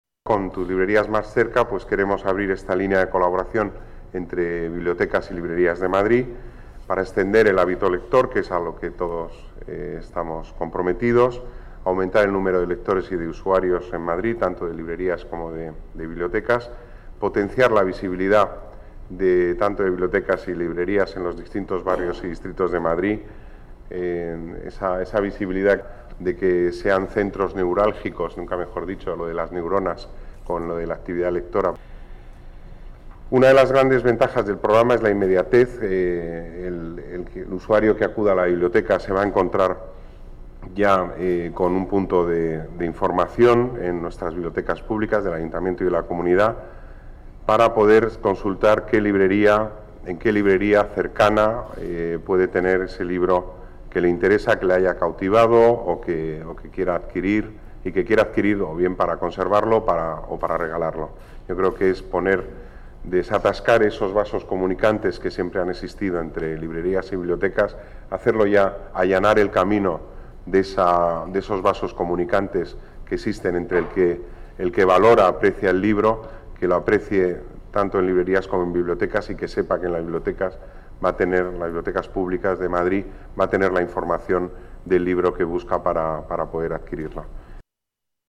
Nueva ventana:Declaraciones de Pedro Corral, delegado de Las Artes